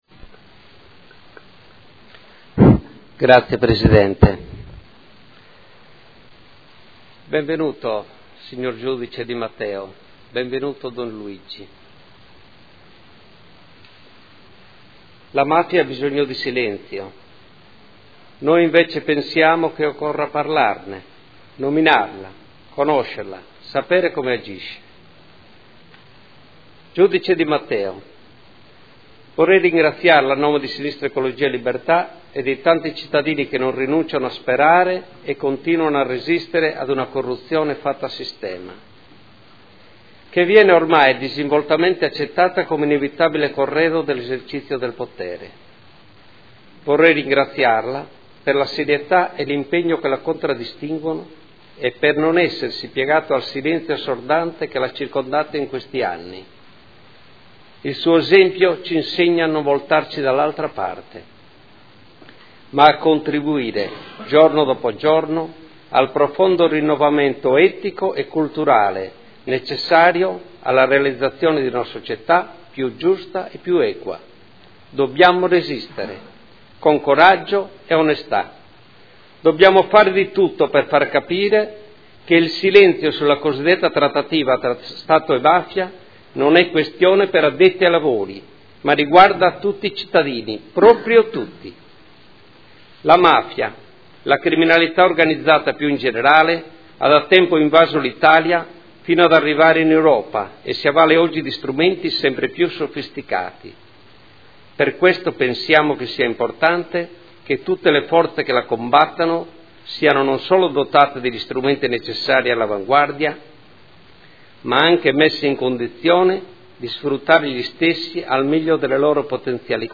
Marco Cugusi — Sito Audio Consiglio Comunale